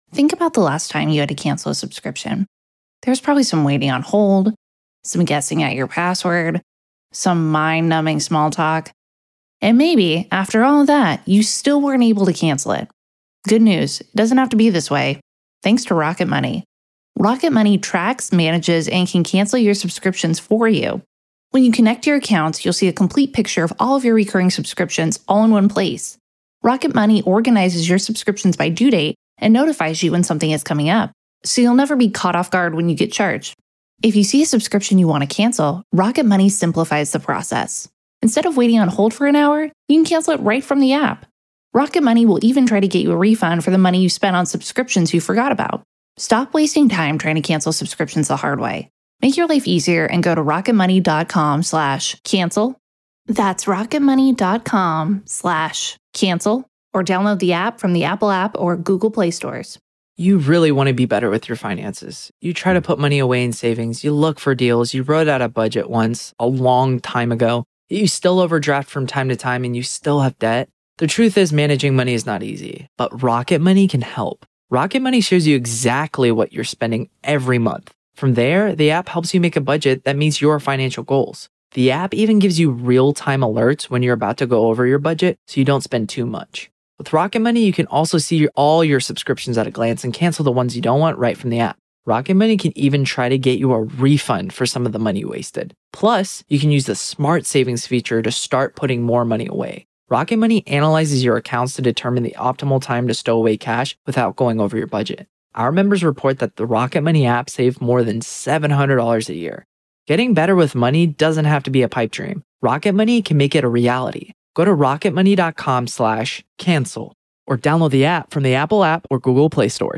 The latest Spanish news headlines in English: 25th January 2021